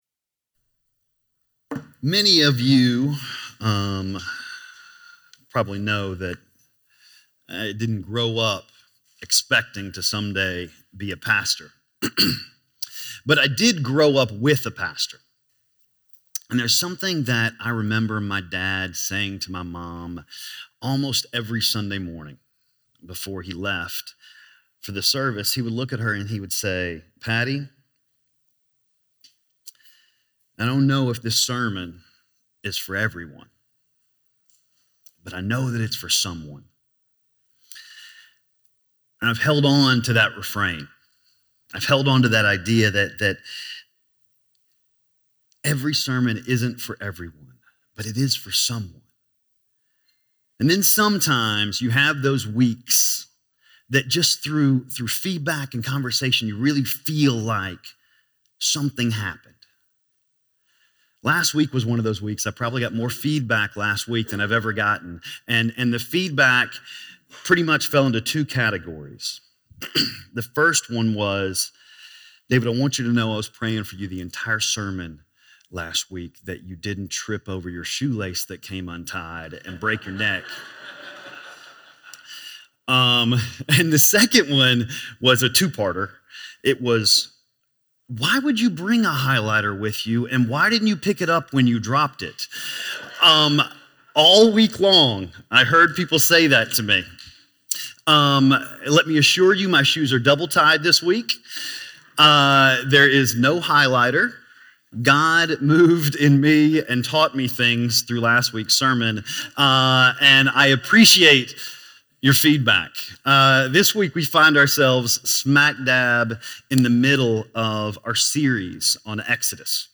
The Evacuation Announced: Moses Equipped - Sermon - Lockeland Springs